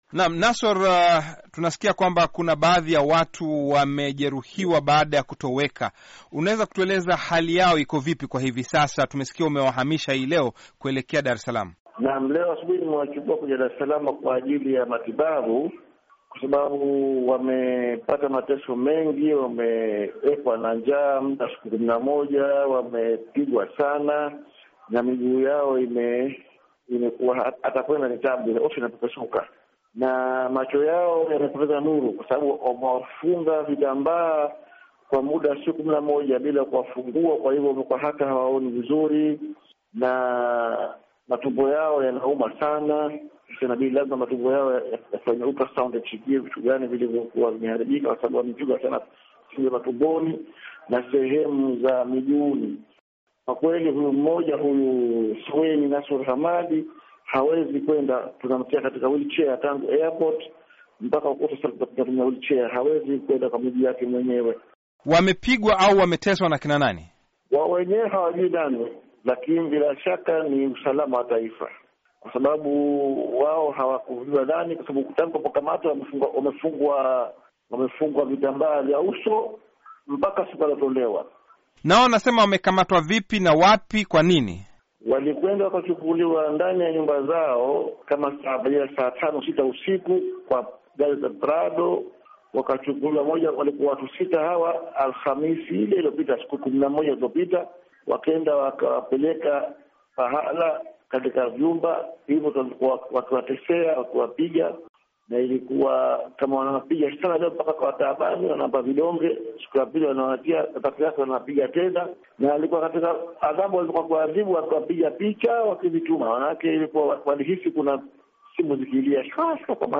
Mahojiano na Nassor A Mazrui CUF Zanzibar